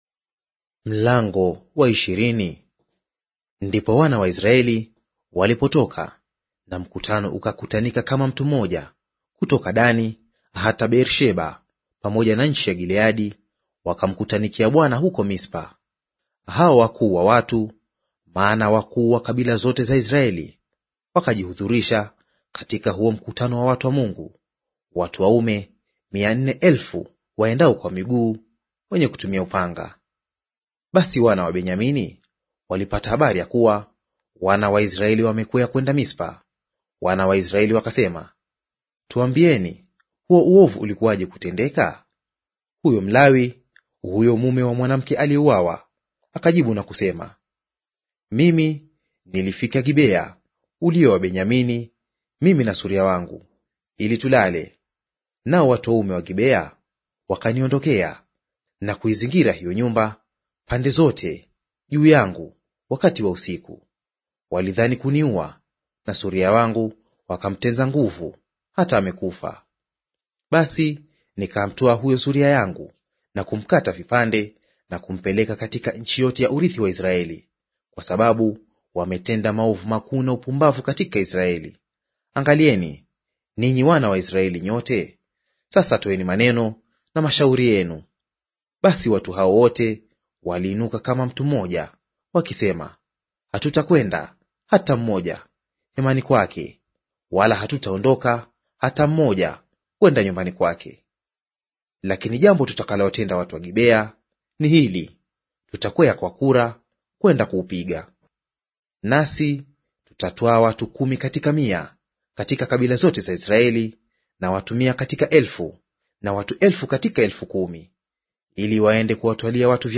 Audio reading of Waamuzi Chapter 20 in Swahili